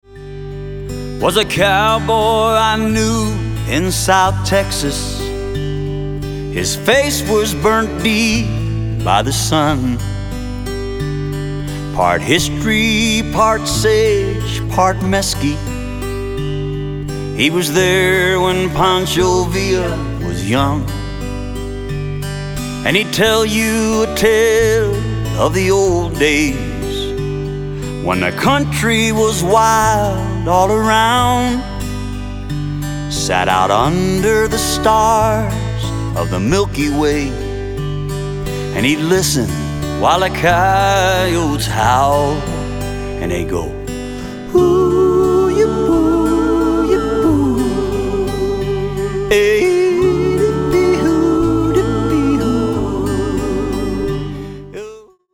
--cowboy songs and folk music